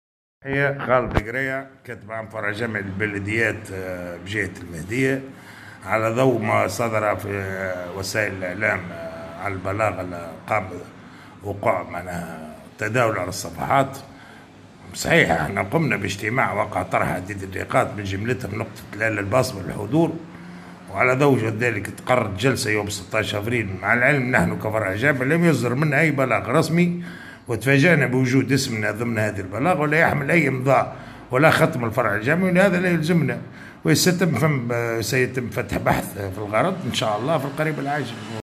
تصريح لمراسل الجوهرة أف أم